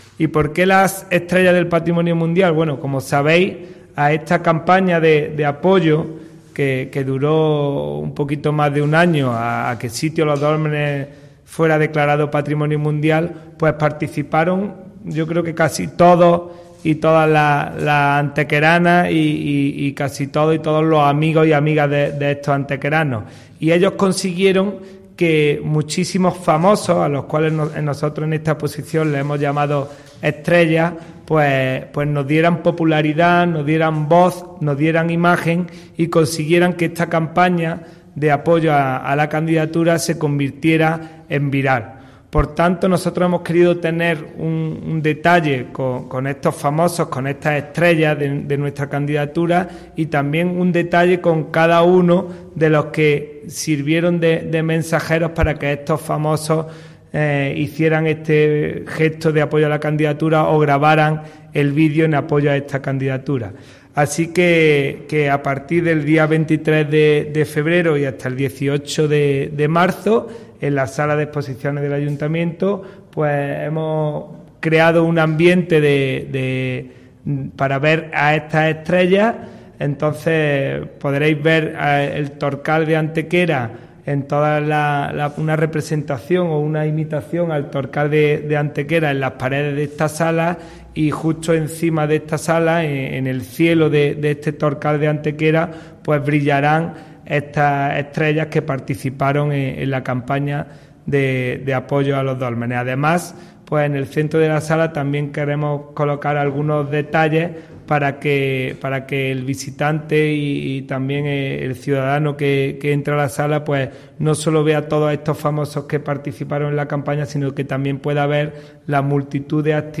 El teniente de alcalde delegado de Hacienda, Juventud y Patrimonio Mundial, Juan Rosas
Cortes de voz J. Rosas 857.5 kb Formato: mp3